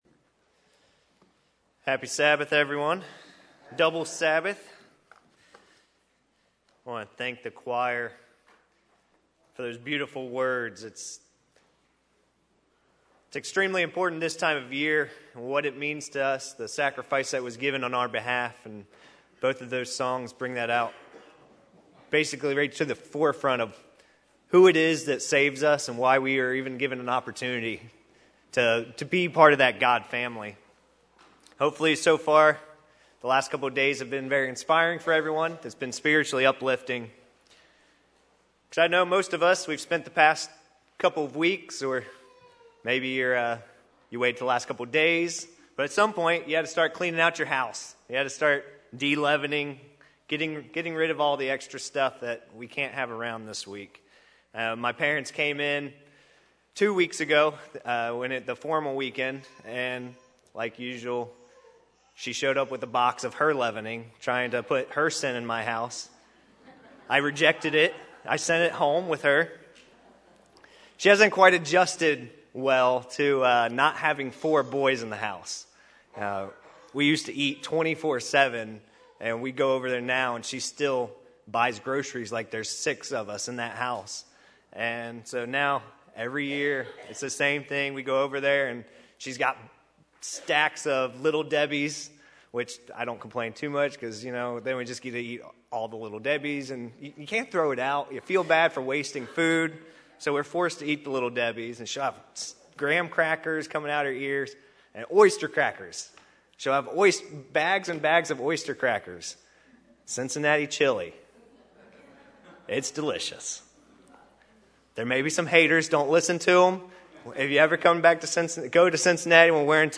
Just like we have to be vigilant in not eating leavening during the Days of Unleavened Bread, we should be watchful at all times to be spiritually clean. This sermon was given on the First Day of Unleavened Bread.